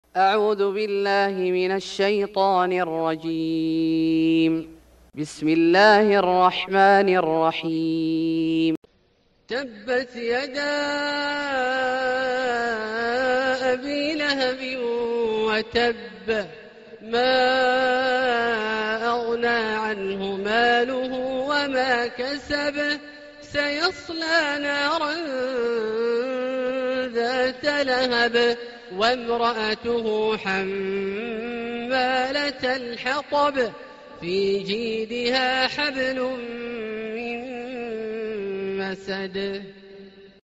سورة المسد Surat Al-Masad > مصحف الشيخ عبدالله الجهني من الحرم المكي > المصحف - تلاوات الحرمين